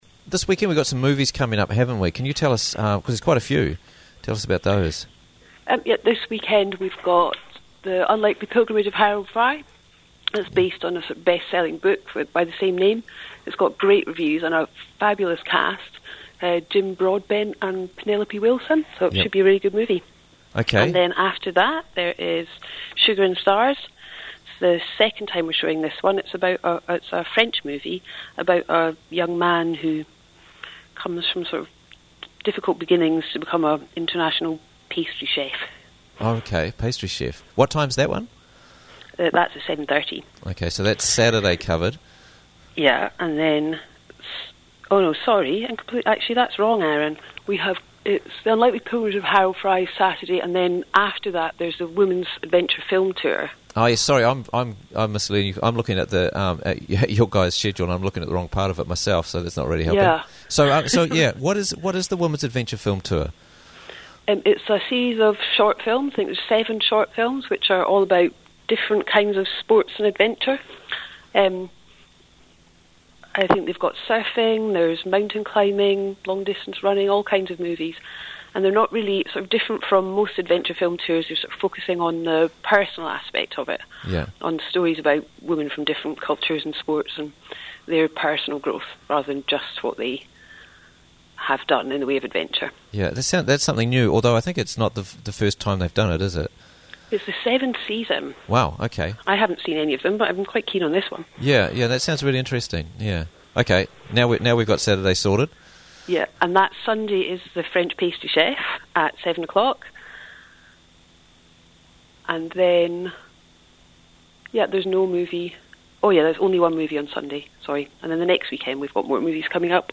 joins us live from the Old School to fill us in on the many activities and events they're running at the moment